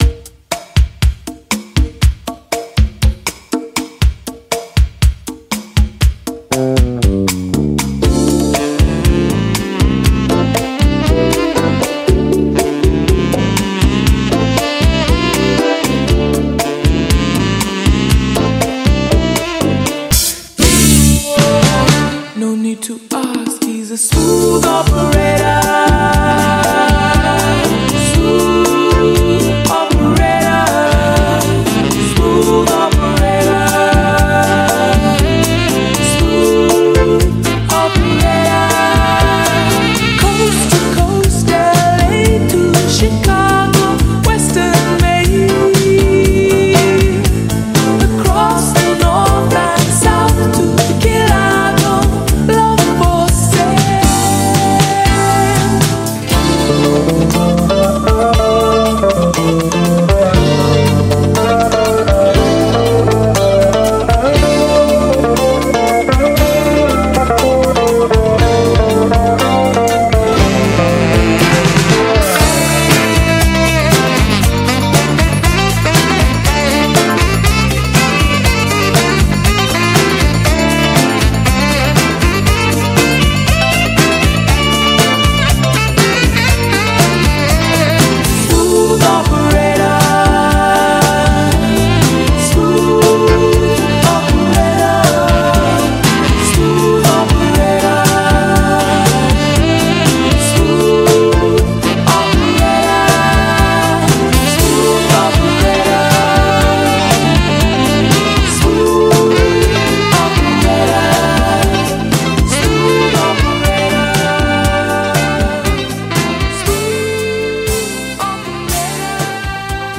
BPM119
Audio QualityMusic Cut